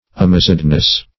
Search Result for " amazedness" : The Collaborative International Dictionary of English v.0.48: Amazedness \A*maz"ed*ness\, n. The state of being amazed, or confounded with fear, surprise, or wonder.
amazedness.mp3